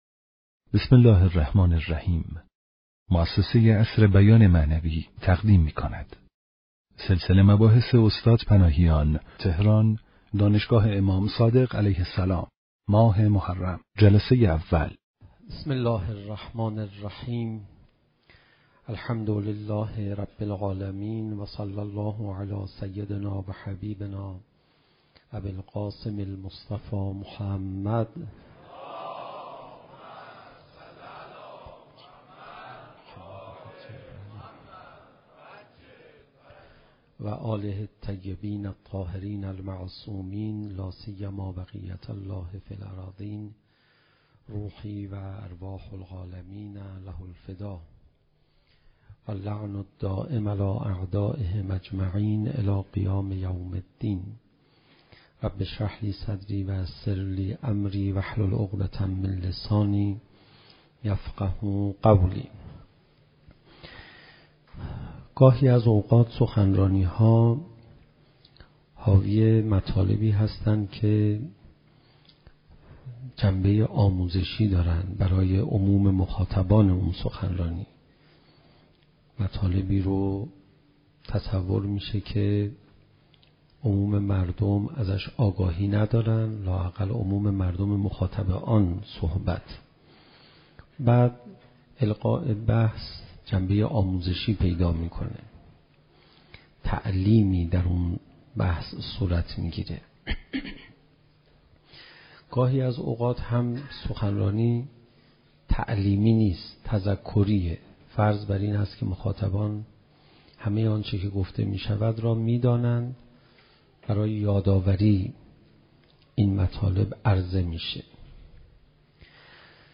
شب اول محرم 95_سخنرانی